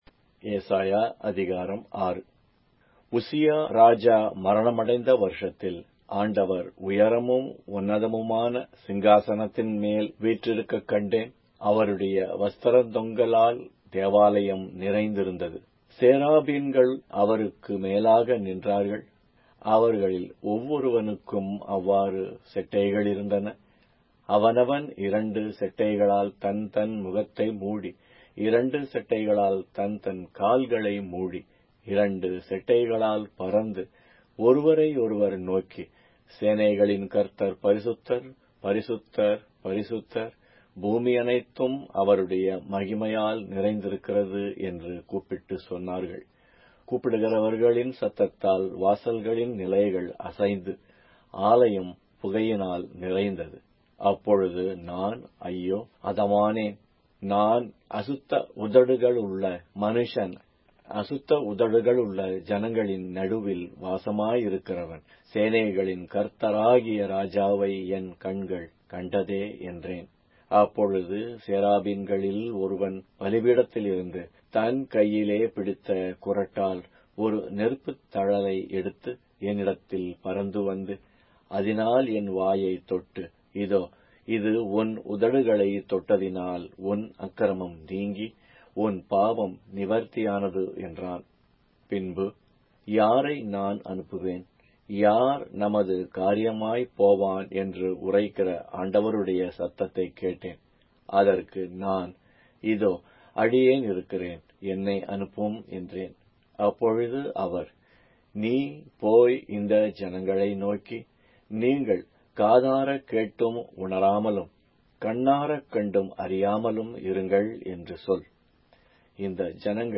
Tamil Audio Bible - Isaiah 64 in Lxxrp bible version